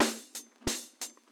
Hatpatternz.wav